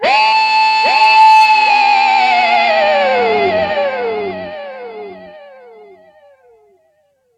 gtdTTE67014guitar-A.wav